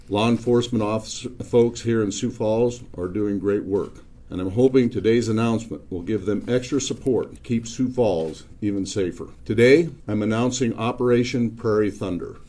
Governor Rhoden announced Operation: Prairie Thunder at a press conference at the Sioux Falls Public Safety Campus.